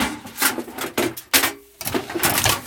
weaponSafe2.wav